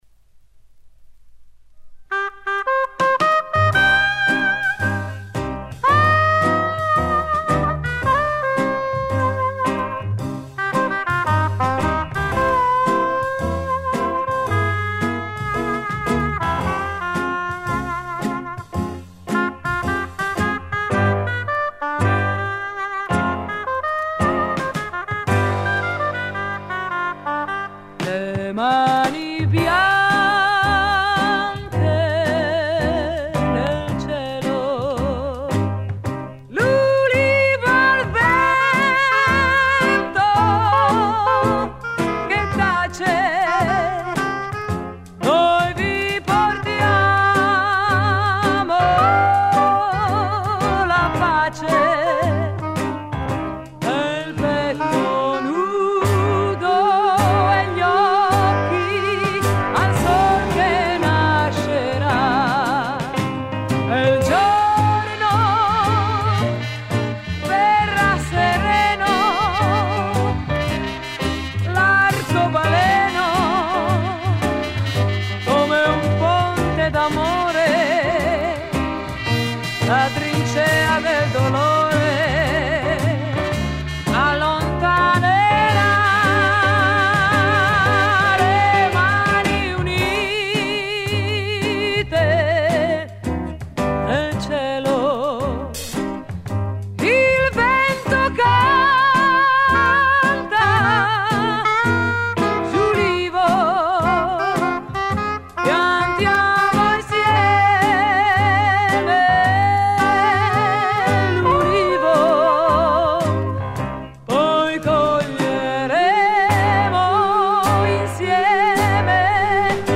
классическое звучание еврейсого твиста